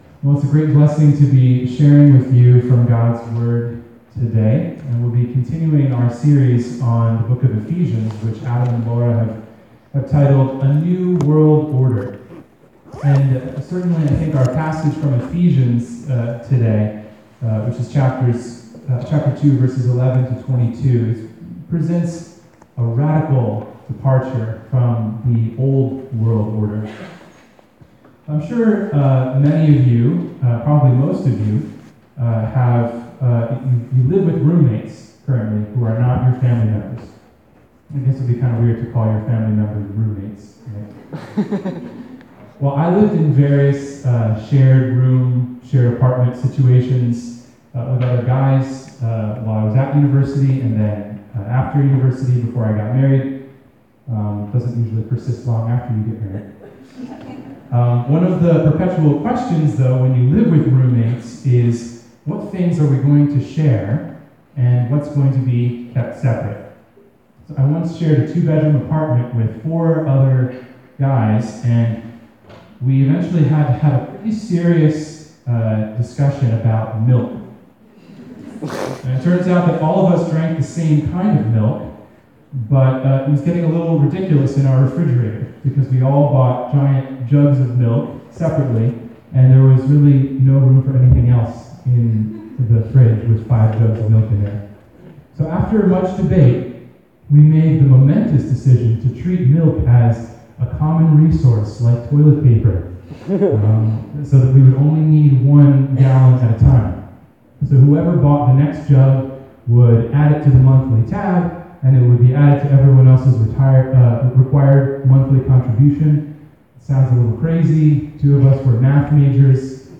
Sermon: New Family, New Temple
This is the audio (20:00, 18.4 MB) of a sermon delivered at a chapel service at LCC on October 11 entitled, “New Family, New Temple.” The text was Ephesians 2:11-22, part of a series of sermons on Ephesians entitled, "A New World Order."